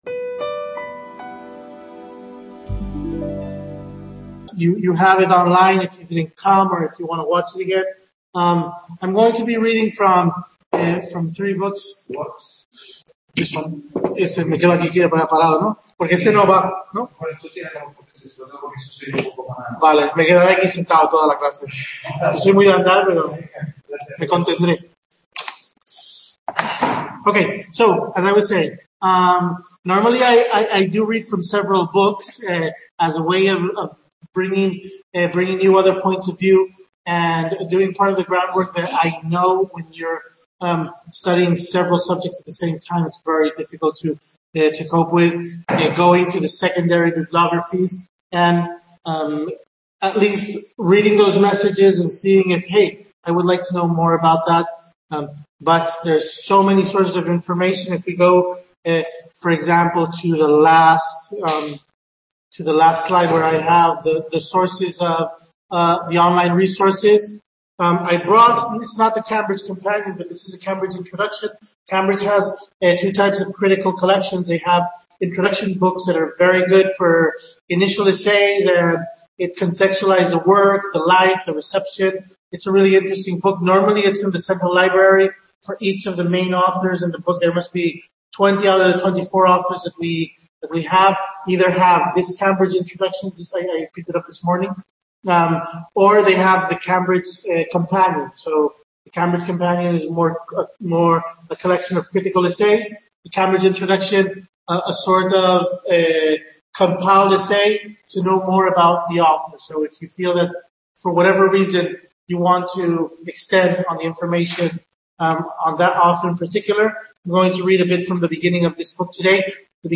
Webconference celebrated on March 4th at the Gregorio Marañón center in Madrid.